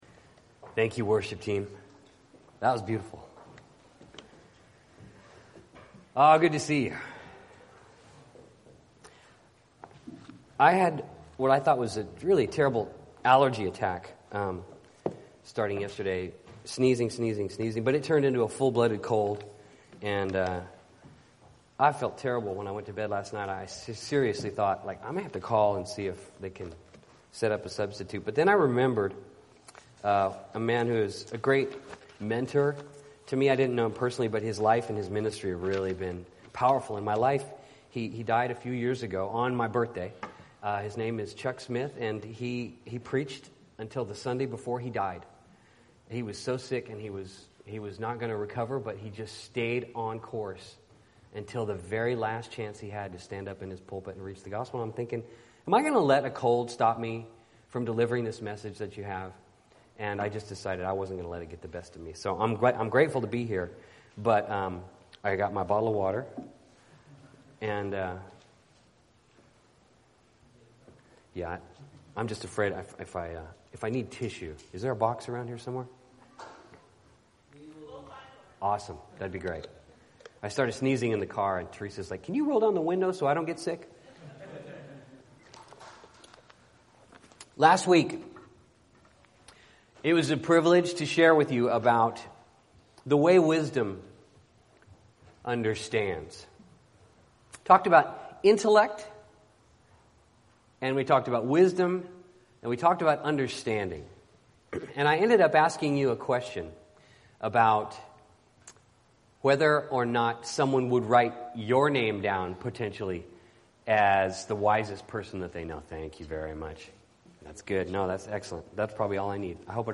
Bible Text: 1 Corinthians 1:18-31 | Preacher